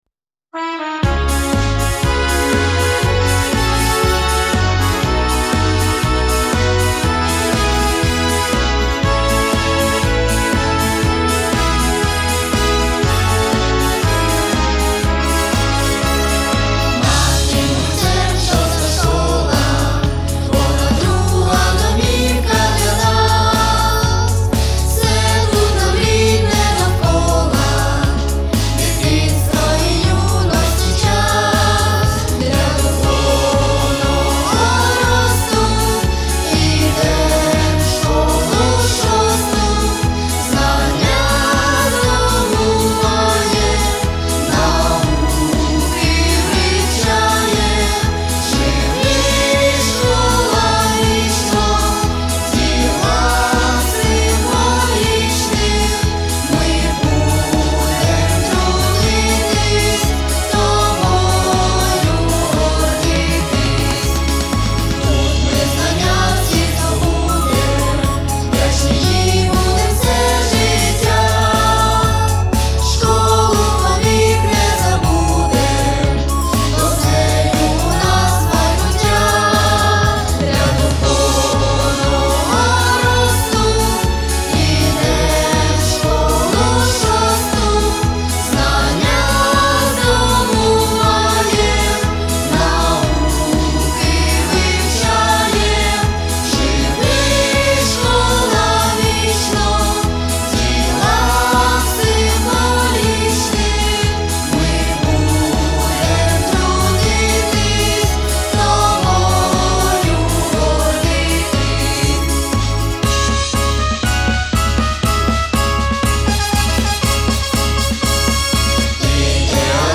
Гимн-хор.mp3